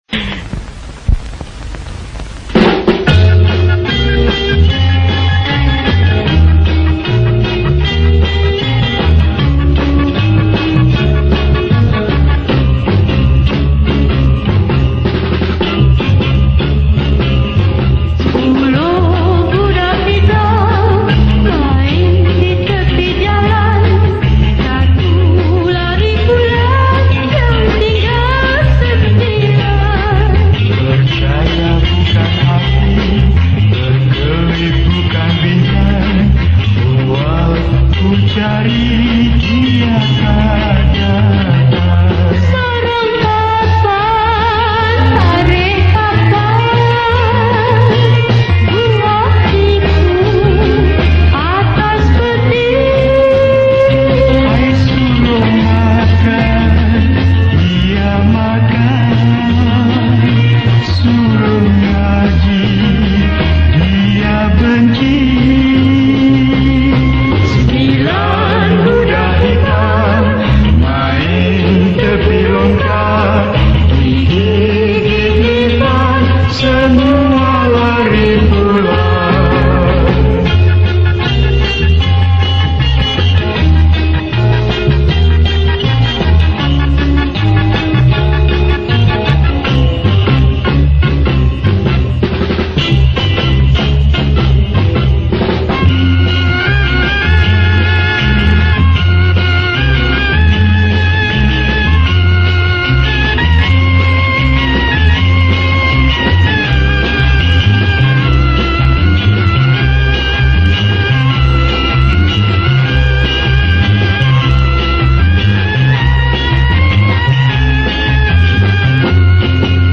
Lagu Kanak-kanak
Pop Yeh Yeh